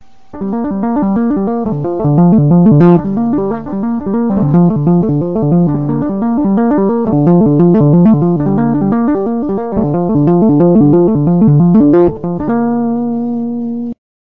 Tapping
Clean
tapping.mp3